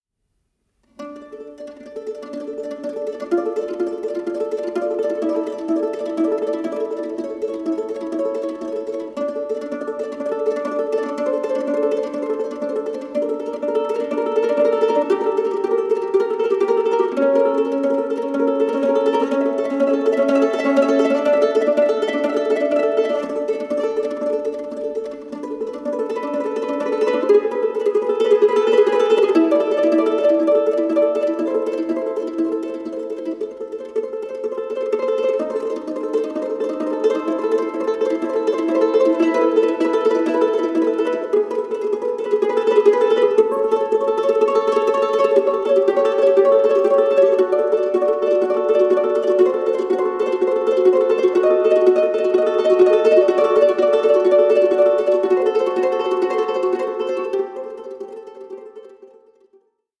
FEATURING MANDOLINS, MANDOLA, LUTE AND BAĞLAMA